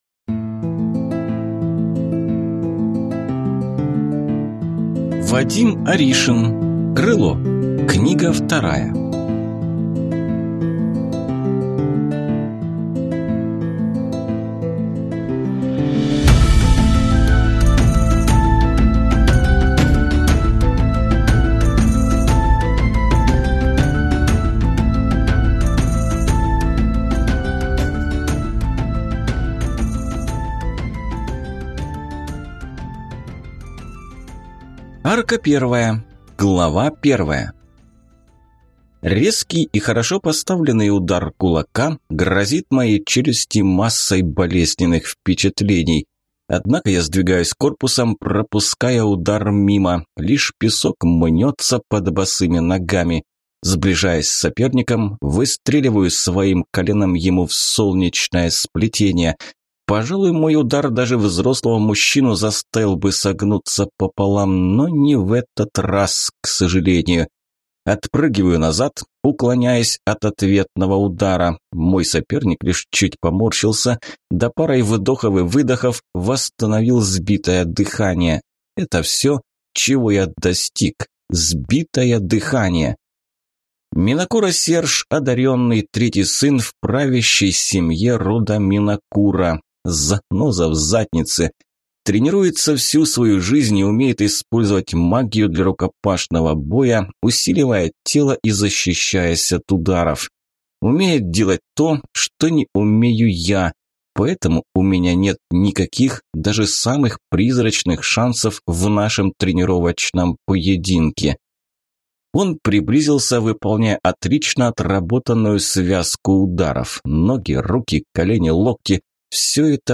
Аудиокнига Крыло. Книга 2 | Библиотека аудиокниг
Прослушать и бесплатно скачать фрагмент аудиокниги